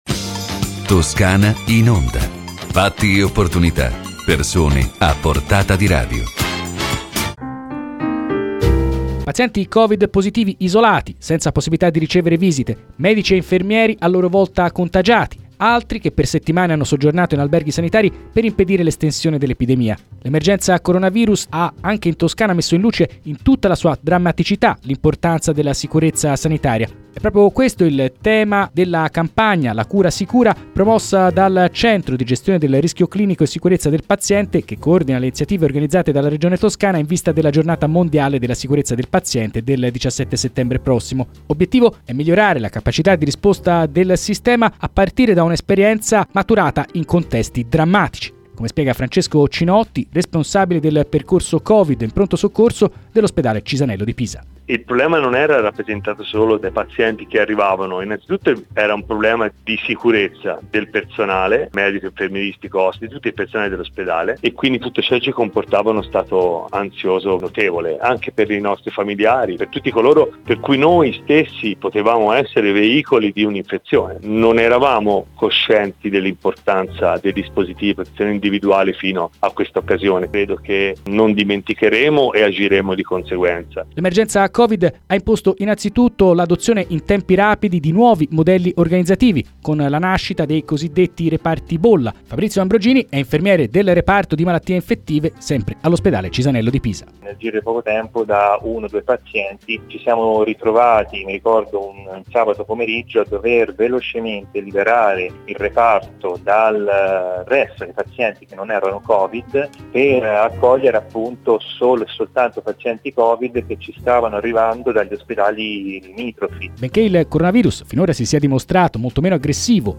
Le interviste radiofoniche
• Stefania Saccardi,  Assessore alla Salute della Regione Toscana